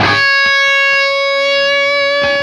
LEAD C#4 CUT.wav